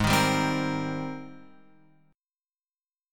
G# Major 9th